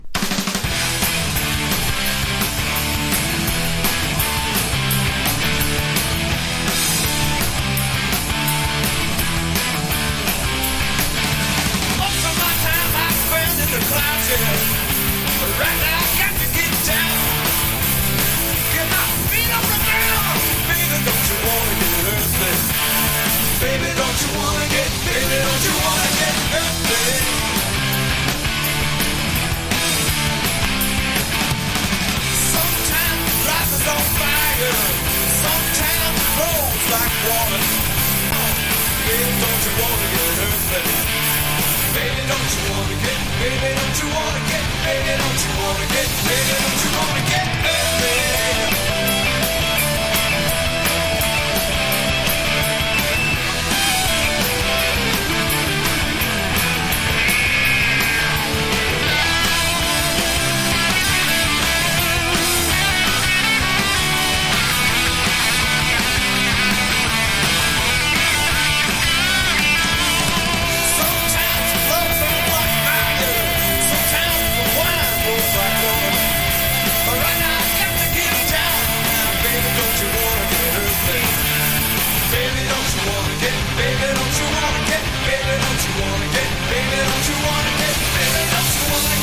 1. 90'S ROCK >
煽りまくるホーンセクションやエレピが絡んだりもする直球のR&Rナンバー多数！
GARAGE ROCK